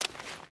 Footsteps / Water / Water Run 1.ogg
Water Run 1.ogg